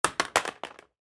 Звуки пульта от телевизора
Звук падения пульта